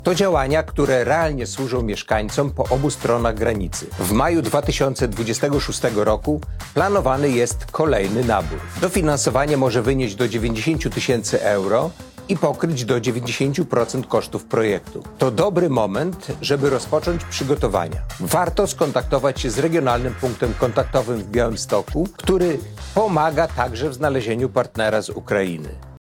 Do udziału w programie zachęca członek zarządu województwa podlaskiego Jacek Piorunek.